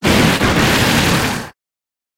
Cri de Shifours Style Mille Poings dans Pokémon HOME.
Cri_0892_Mille_Poings_HOME.ogg